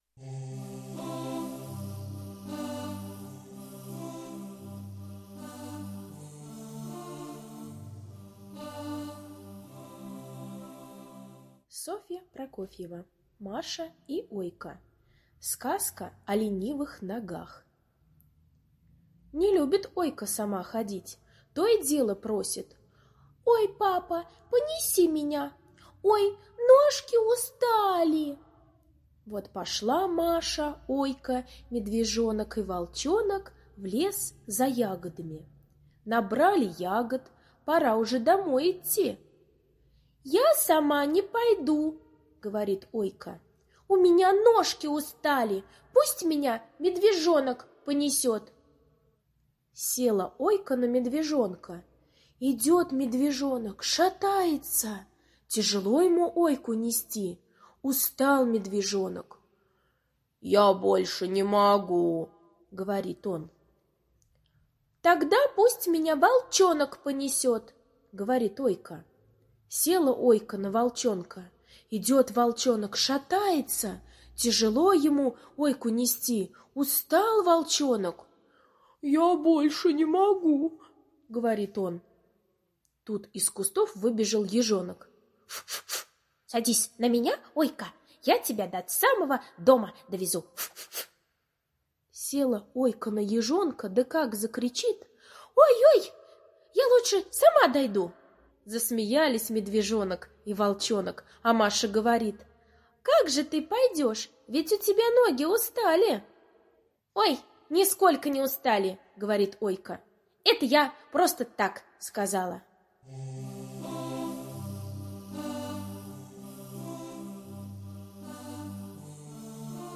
Сказка о ленивых ногах - аудиосказка Прокофьевой С. История о том, как Ойка не хотела сама идти, просила ее донести.